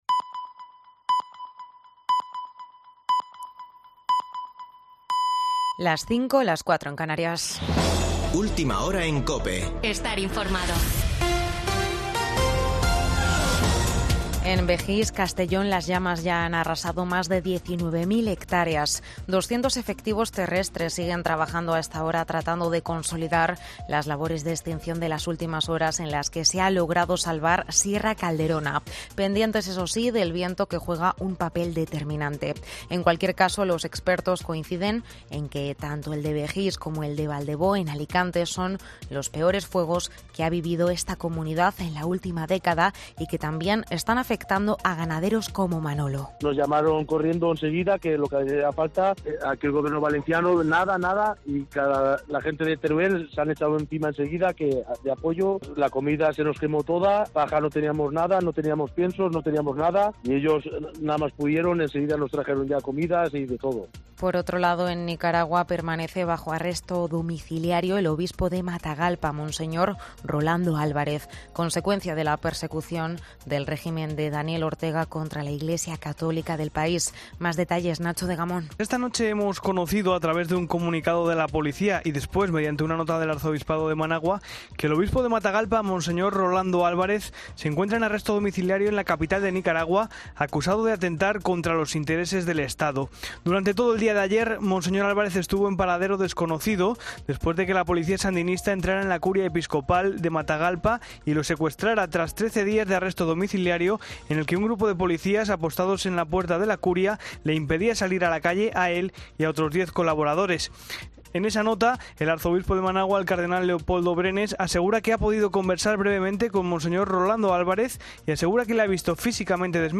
AUDIO: Boletín de noticias de COPE del 20 de agosto de 2022 a las 05.00 horas